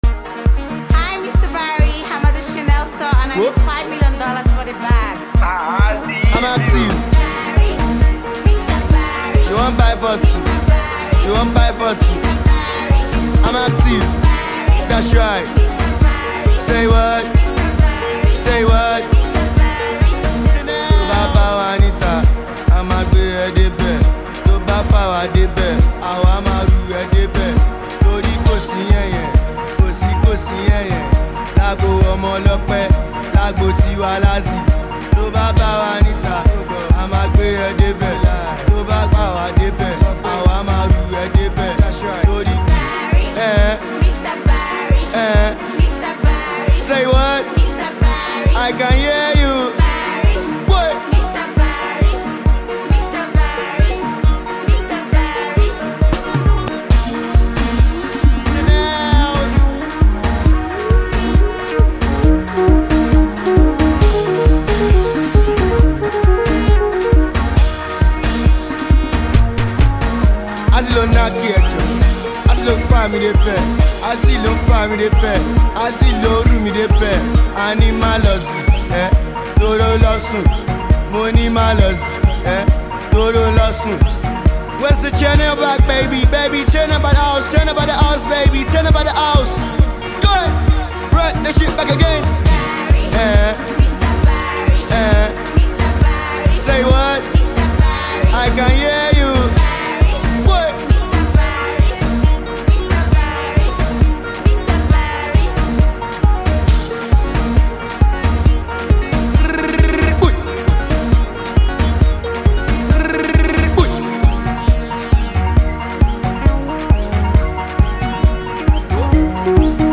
Multi-talented singer